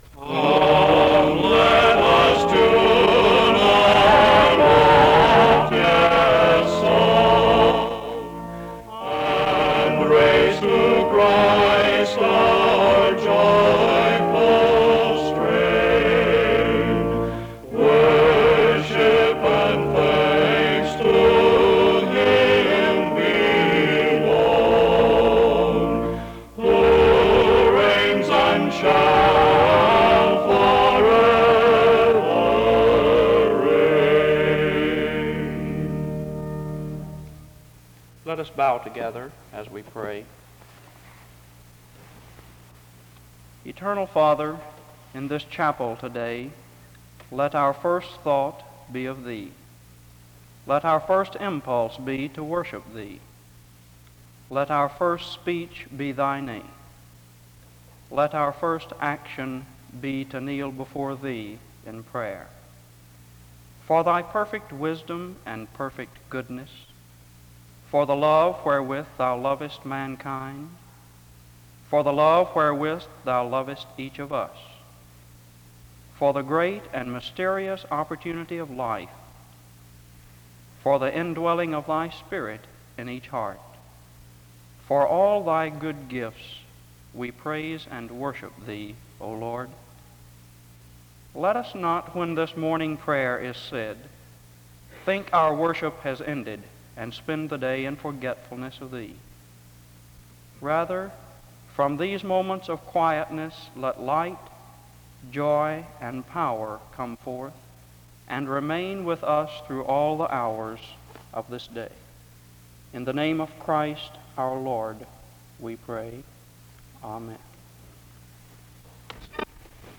The service begins with opening music from 0:00-0:37. A prayer is offered from 0:38-1:57. A time of meditative prayer takes place from 2:08-4:00. A solo performance is sung from 4:03-6:54.
Music plays from 9:08-16:14.
A final period of meditative prayer occurs from 17:53-22:32.
In Collection: SEBTS Chapel and Special Event Recordings SEBTS Chapel and Special Event Recordings